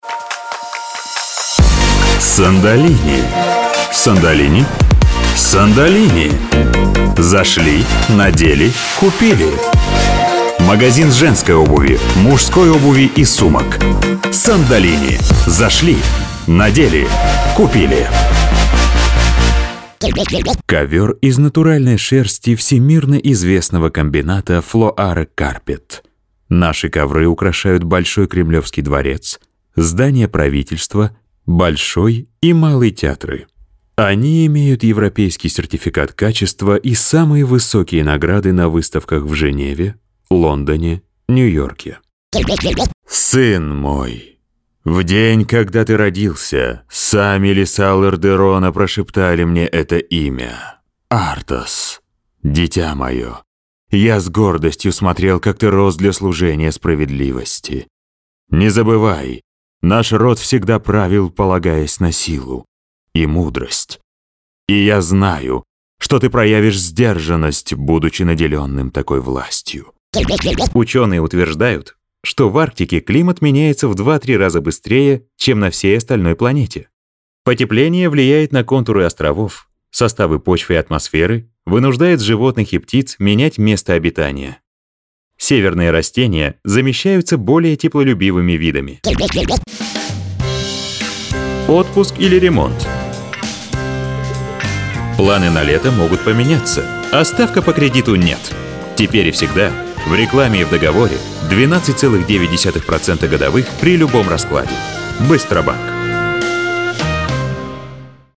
Скачать демо диктора
Диктор, актёр озвучки.
Рабочее оборудование: Ноутбук ASUS 11th i3, Внешняя звуковая карта AVID Fast Track Solo, Конденсаторный микрофон Fame Studio C05, Отдельное помещение для записи, отделанное акустическим поролоном 50 мм (плитка) и акустическими панелями.